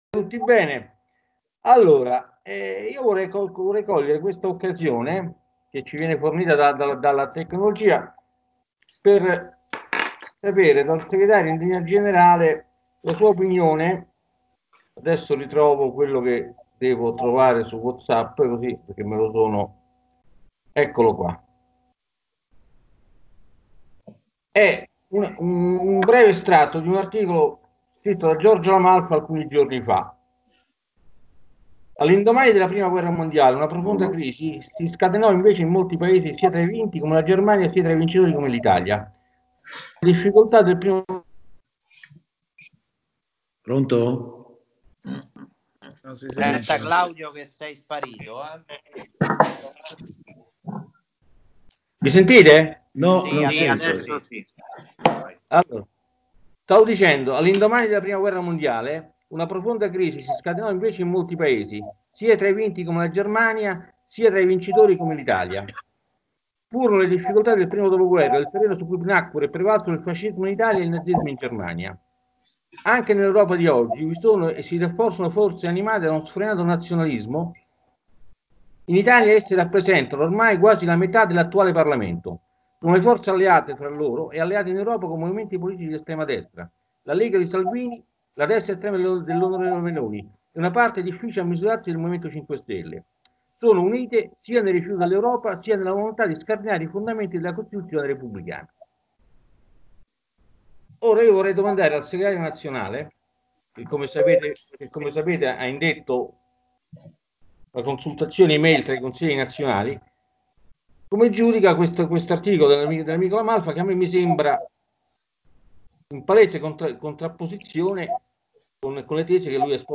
Registrazione audio dell'incontro del 28 Aprile 2020 Hanno partecipato alla linea diretta